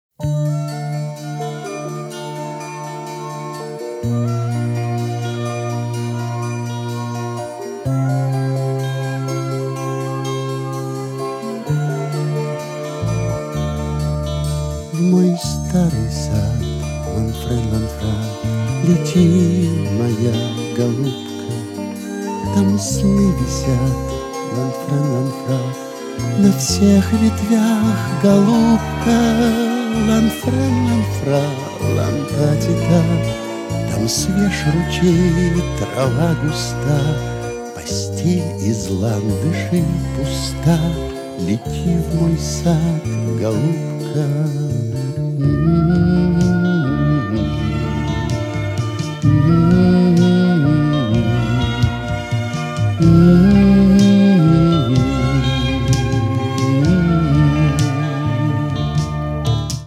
• Качество: 320, Stereo
мужской вокал
мелодичные
спокойные
OST
романтические
Фрагмент песни из кинофильма